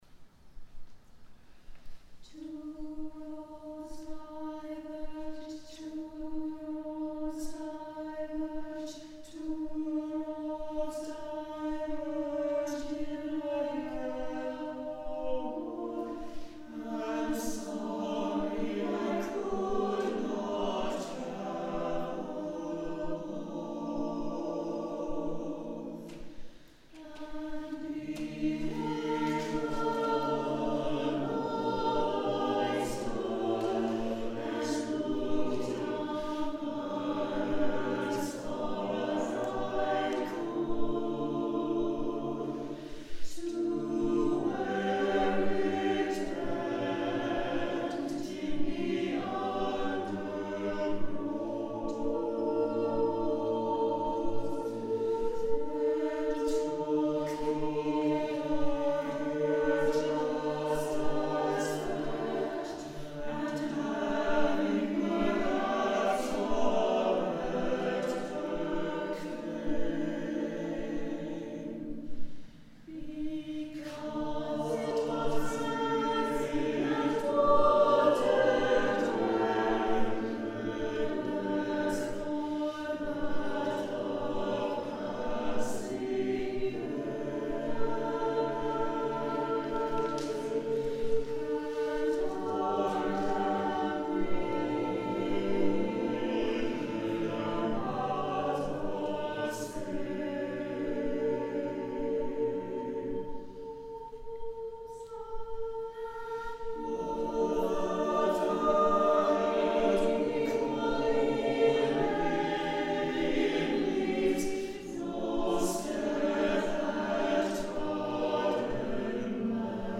Rehearsal Recordings
This is just straight-up singing albeit 6 feet apart, masked, and with ambient noise through the open doors and windows ... no do-overs, no stopping and starting, just pressing record at a rehearsal!